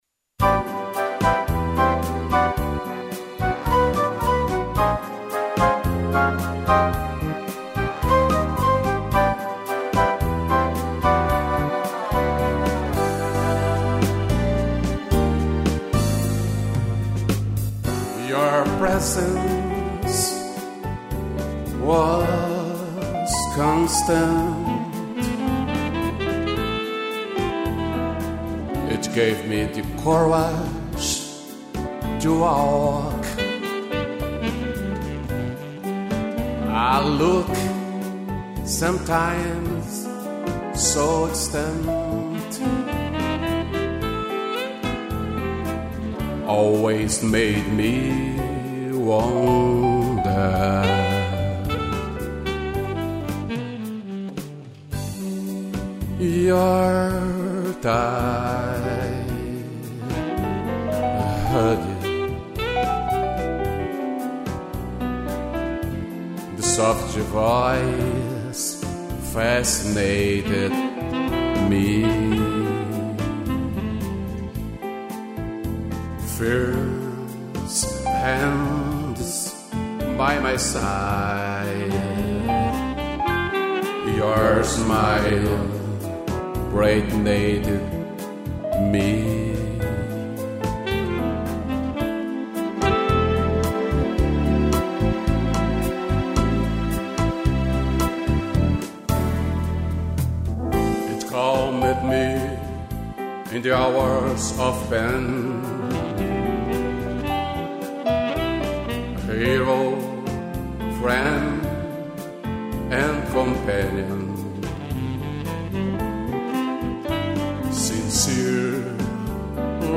piano
sax